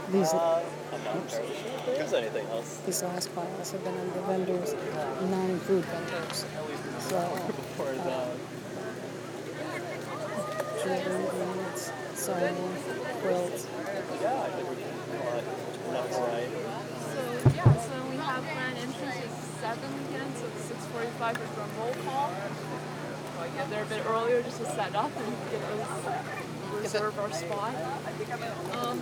Menominee POWWOW